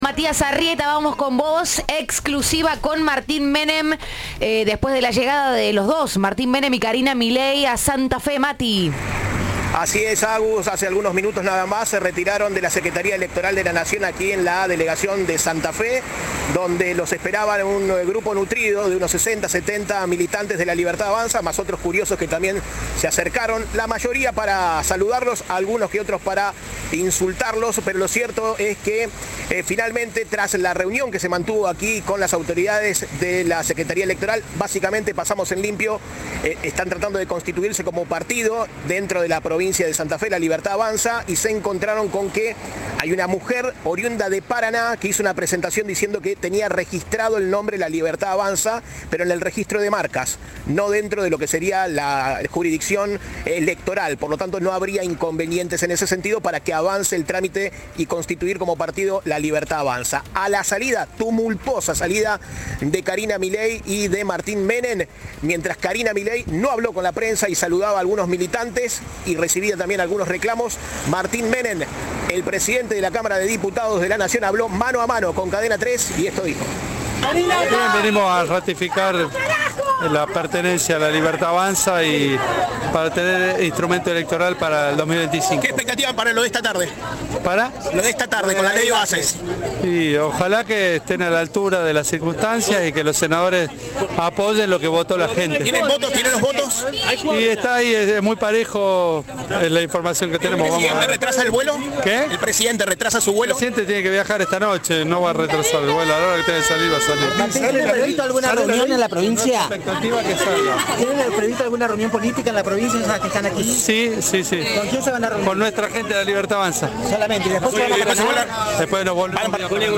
El presidente de la Cámara de Diputados se refirió a la votación en la Cámara Alta. "Todo está muy parejo, es la información que tenemos", dijo en diálogo con Cadena 3.
Informe